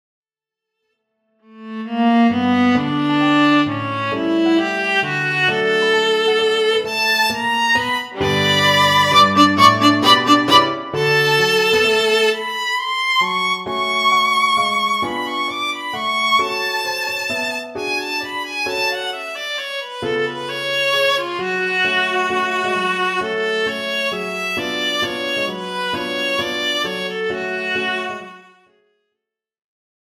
Besetzung: Violine